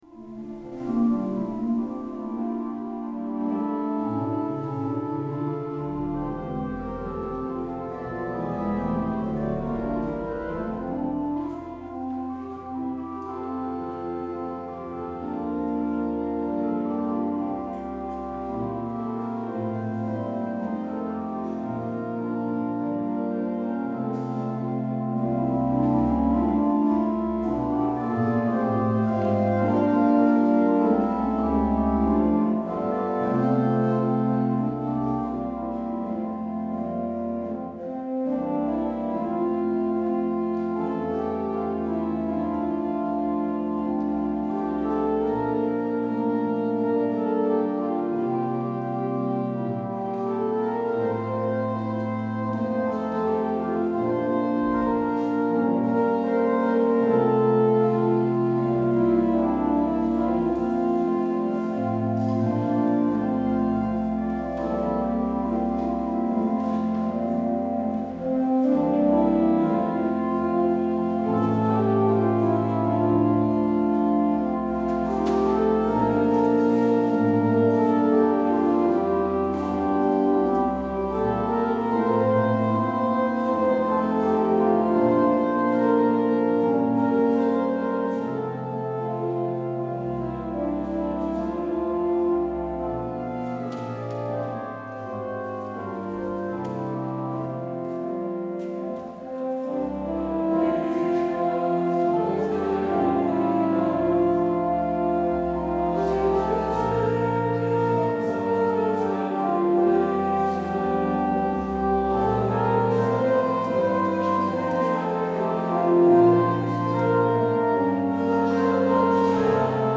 Luister deze kerkdienst hier terug
Het openingslied is “Wij groeten U”. Als slotlied hoort u “Sterre der Zee”.